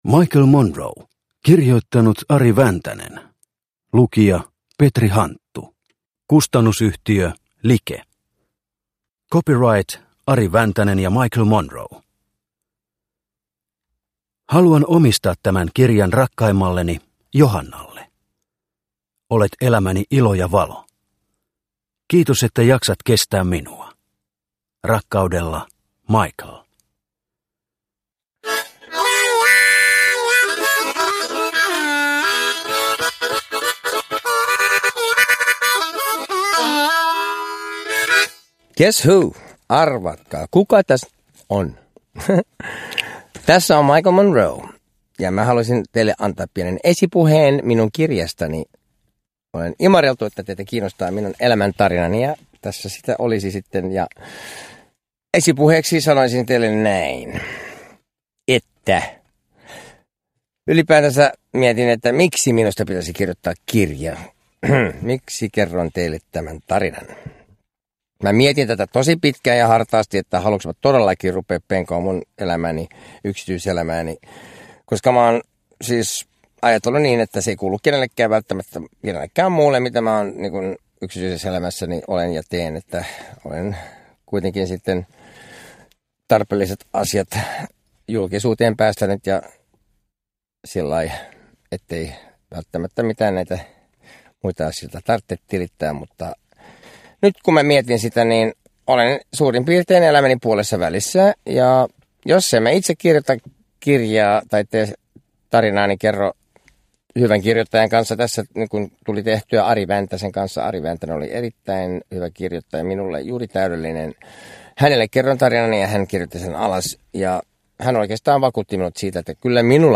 Michael Monroe (mp3) – Ljudbok – Laddas ner